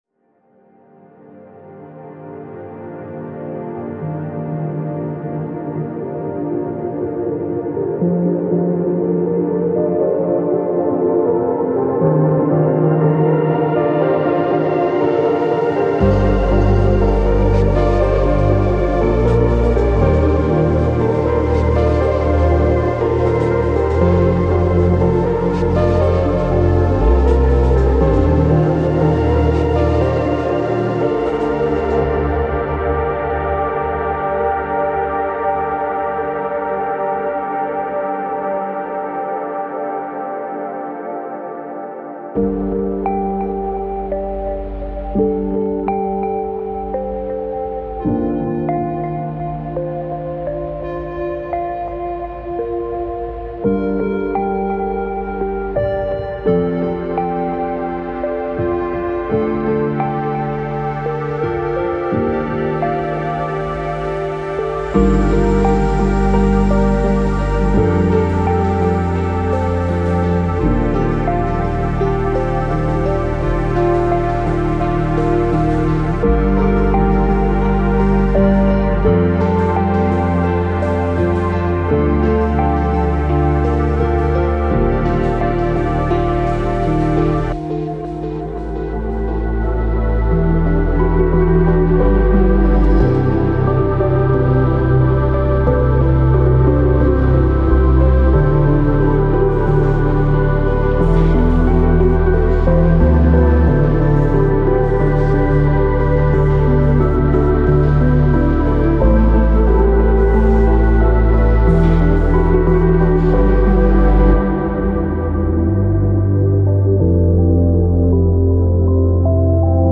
码率：24Bit – 48kHz
声道数：立体声